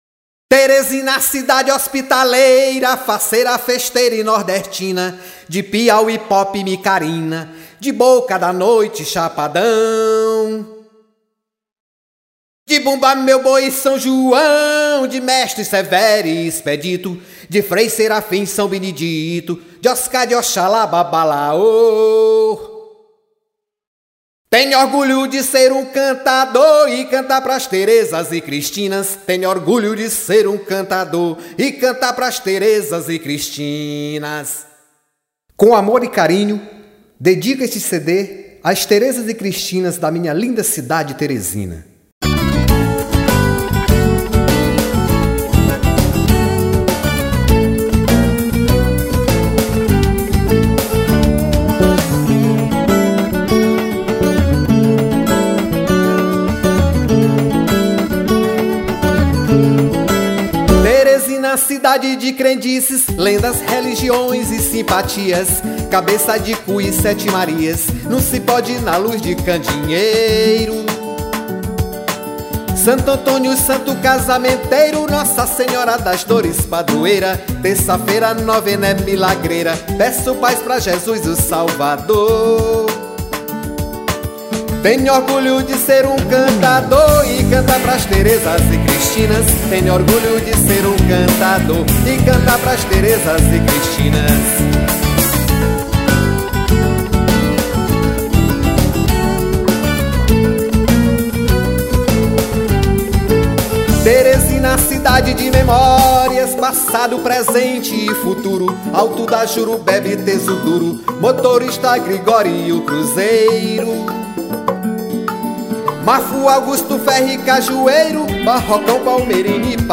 620   04:52:00   Faixa:     Rock Nacional